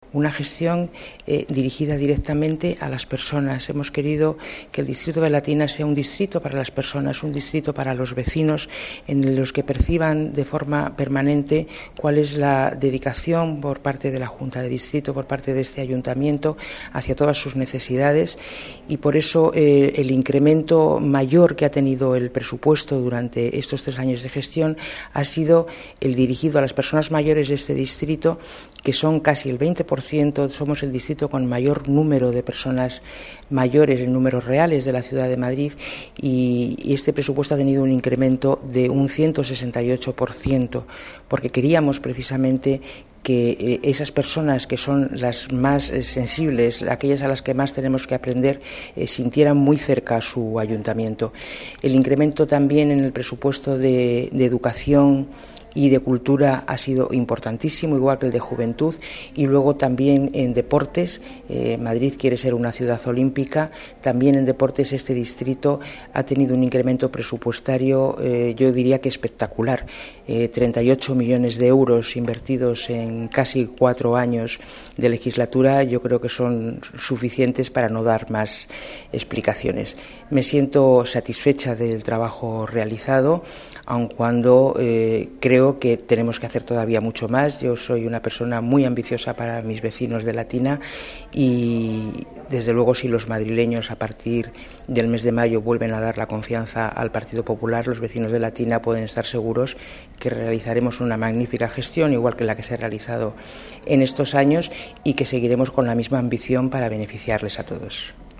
Nueva ventana:La Concejala del Distrito de Latina, Mª Nieves Sáez de Adana, hace balance de la gestión 2003-2006 de su Junta Municipal